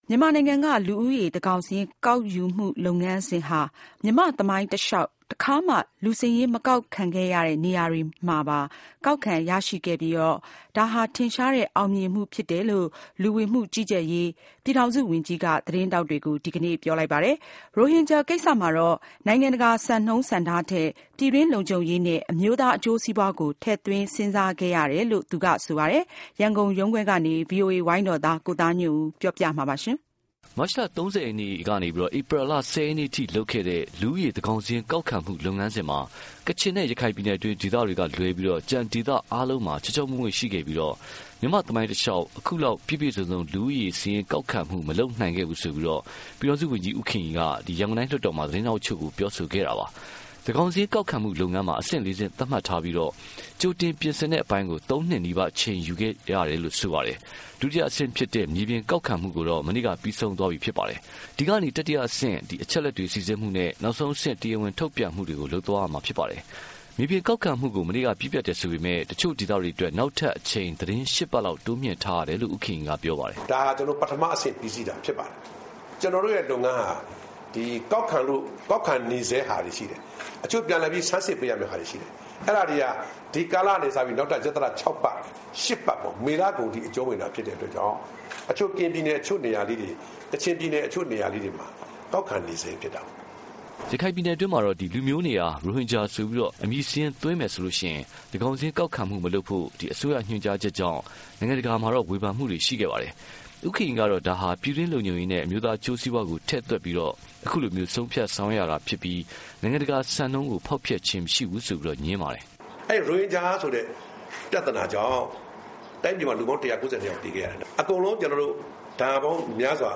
သန်းခေါင်စာရင်း-သတင်းစာရှင်းလင်းပွဲ